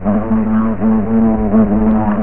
fs_wasp1.wav